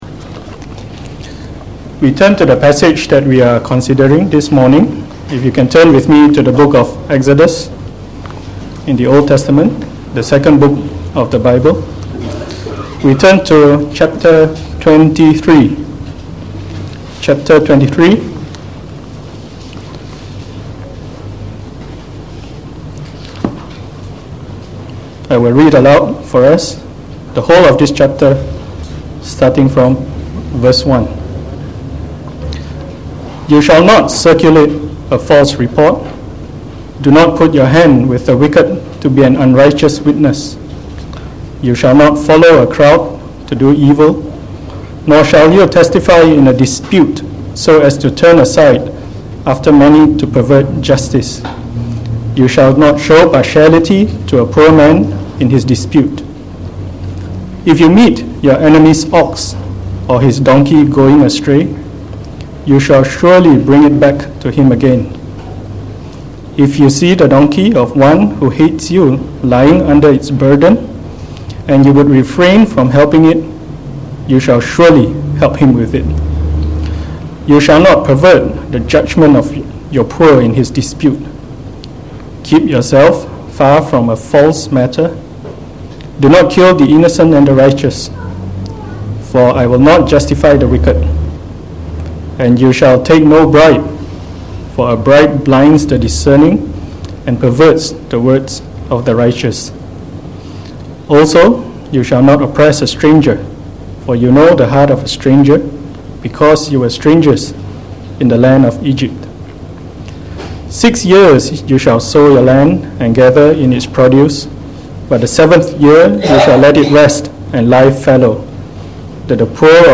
Sermon on the book of Exodus delivered in the Morning Service